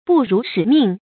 不辱使命 bù rǔ shǐ mìng
不辱使命发音